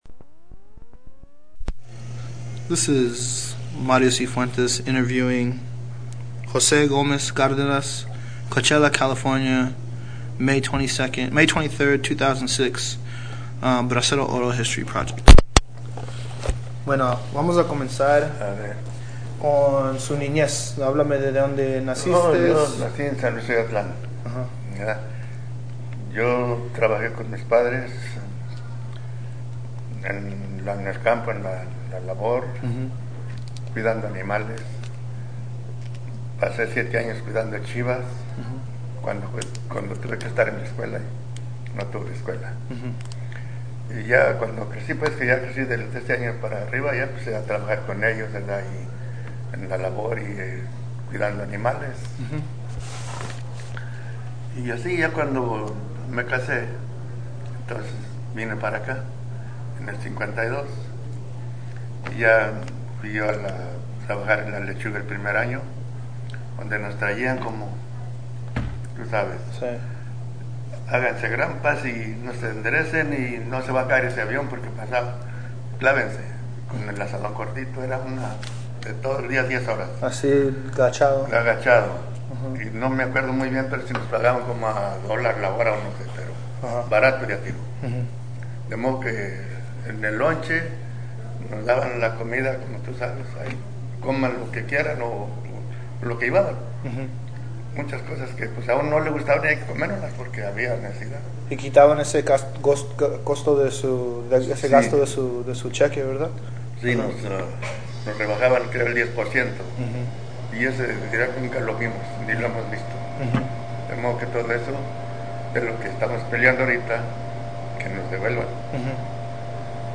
Original Format Mini Disc
Location Coachella, CA